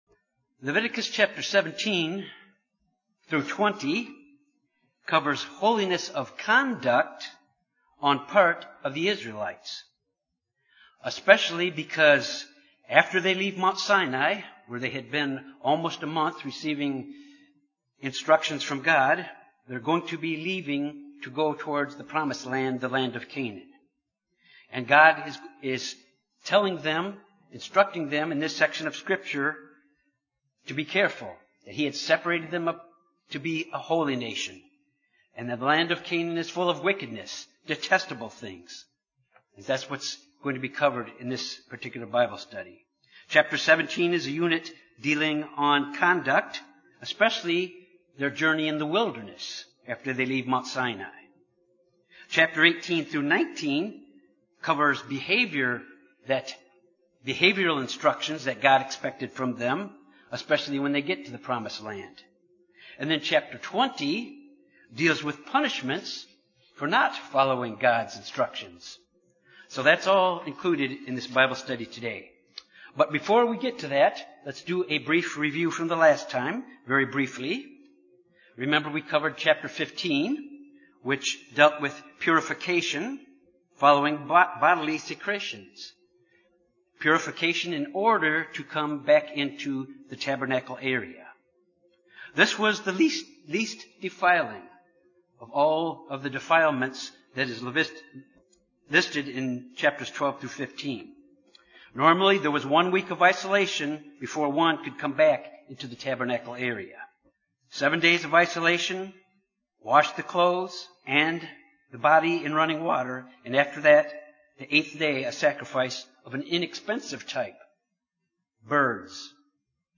This Bible study deals with God’s instructions to the Children of Israel concerning ceremonial defilement in the wilderness and moral purity when they entered into the promised land.